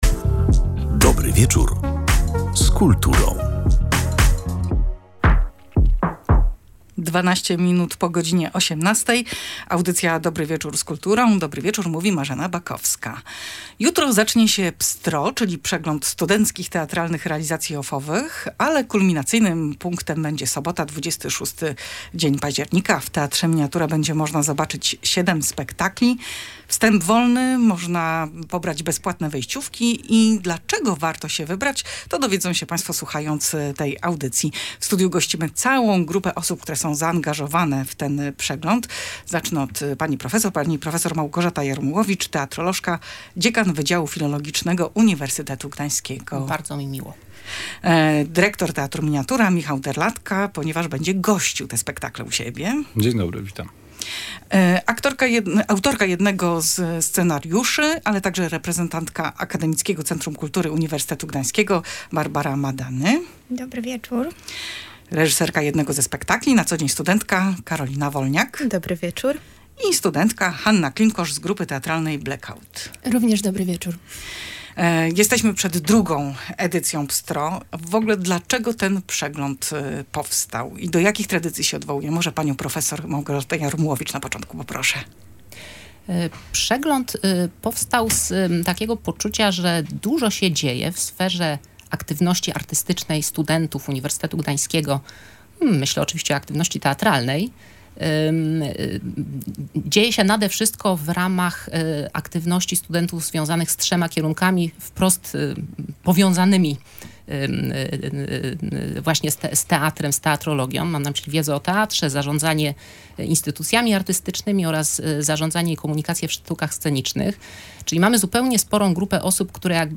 O tym, dlaczego warto się wybrać na przegląd, opowiadali w audycji „Dobry Wieczór z Kulturą”: